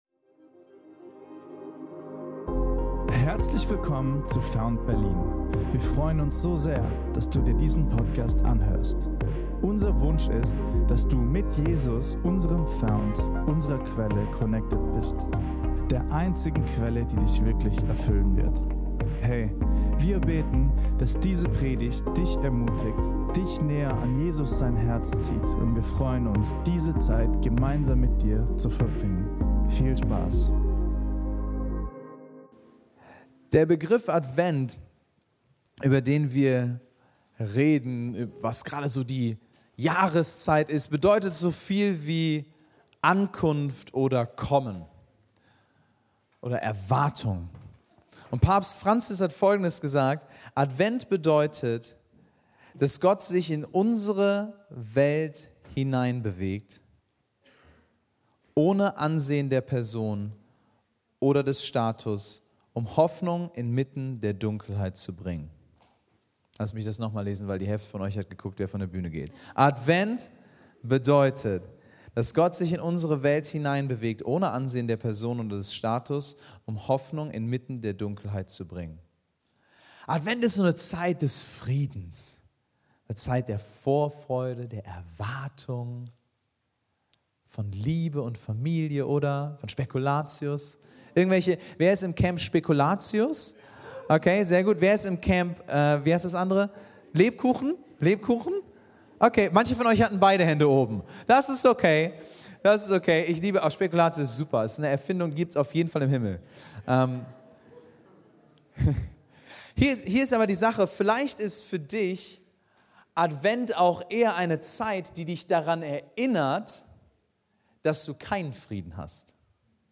in dieser Predigt zum Start der Adventszeit